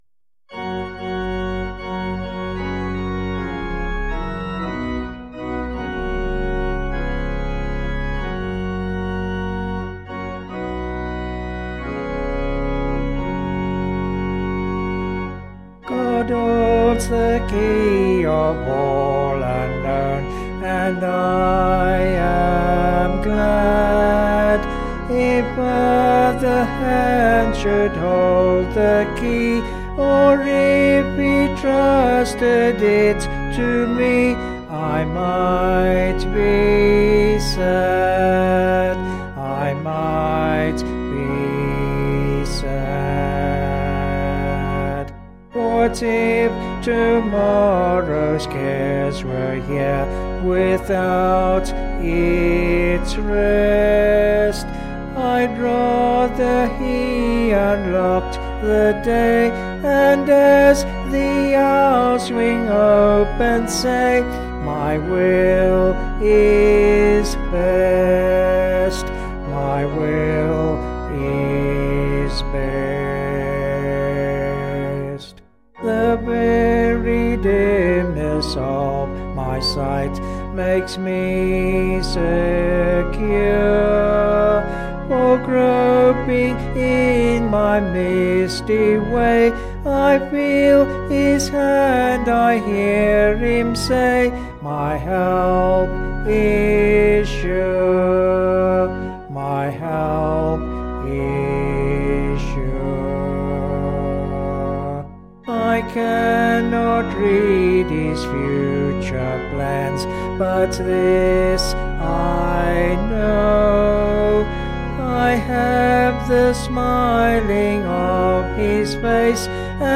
Vocals and Organ   265.1kb Sung Lyrics